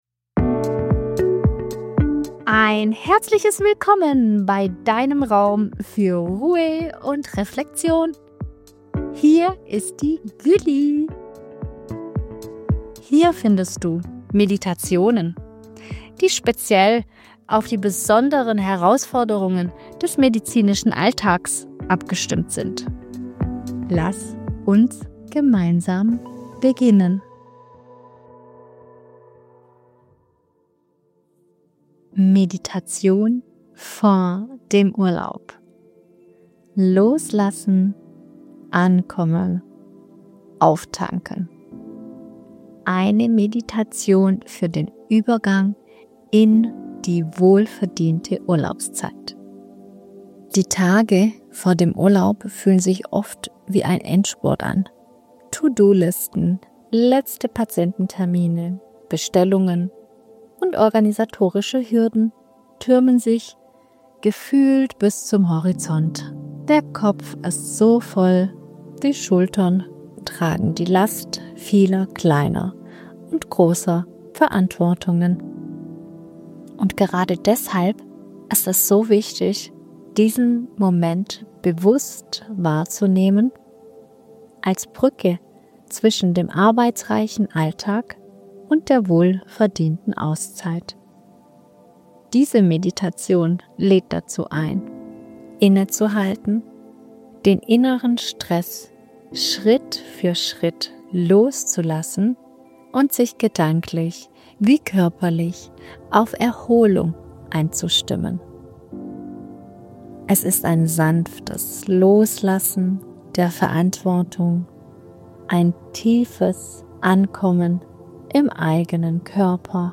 In dieser Episode führe ich Dich durch eine entspannende